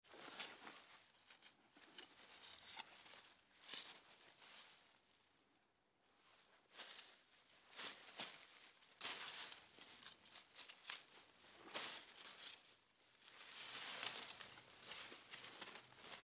Звуки кустов
Звук человека, прячущегося в кустах